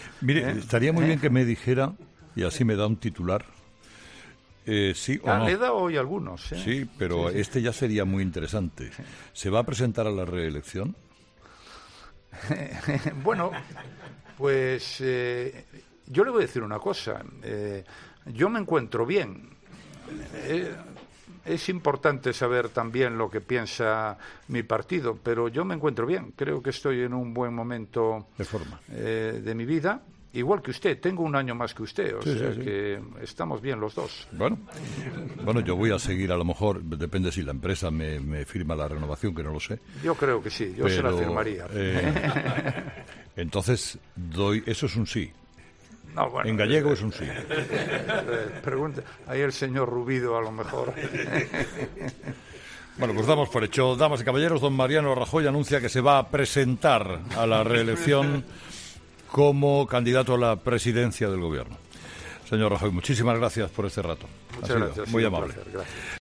Rajoy no descarta presentarse a reelección
Mariano Rajoy en el estudio de la Cadena COPE durante la entrevista con Carlos Herrera.